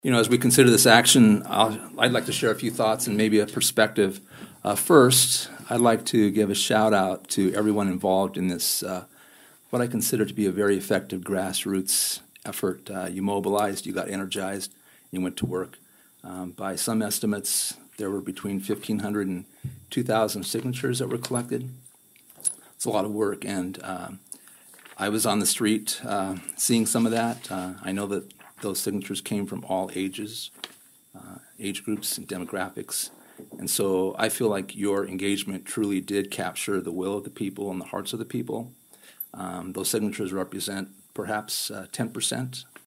ABERDEEN, S.D (Hub City Radio)- At the Aberdeen, SD City Council meeting earlier this Monday evening, October 6th, the City Council voted unanimously on an 8-0 vote to keep downtown Main Street as a one-way road.
Councilman Alan Johnson who was opposed to two-way Main acknowledged the hard work citizens did to likely put this to a vote of the people.